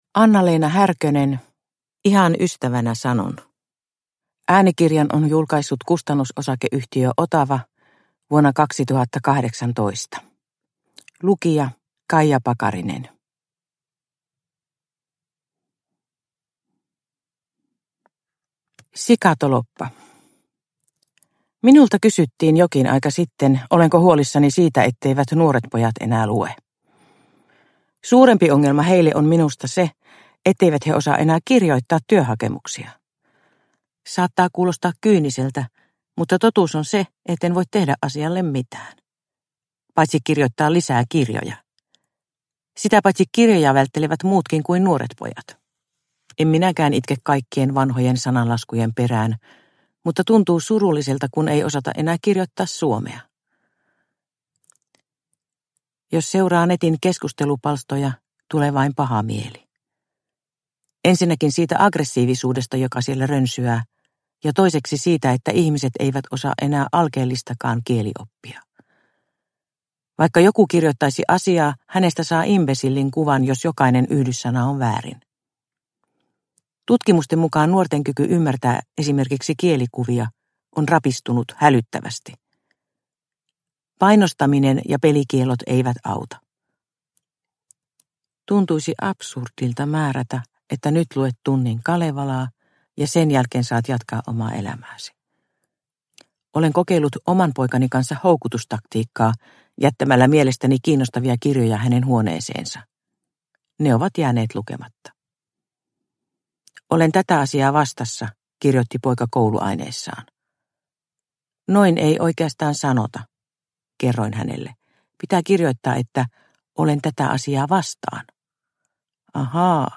Ihan ystävänä sanon ja muita kirjoituksia – Ljudbok – Laddas ner